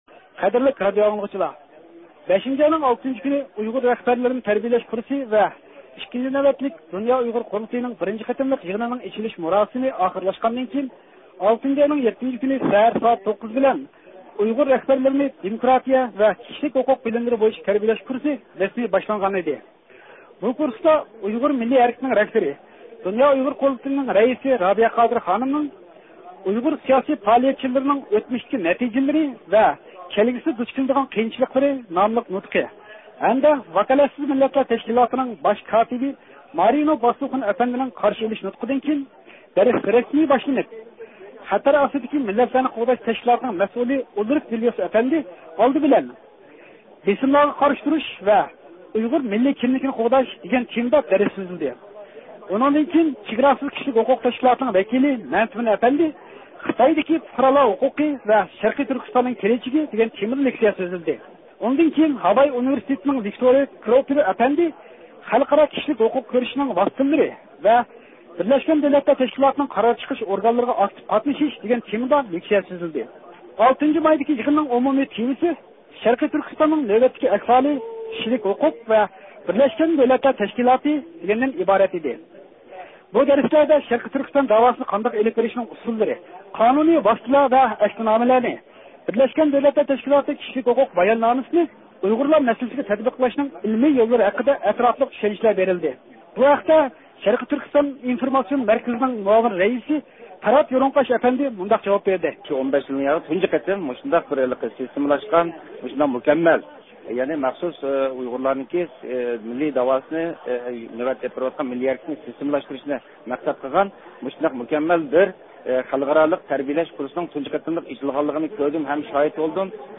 ئىختىيارى مۇخبىرىمىز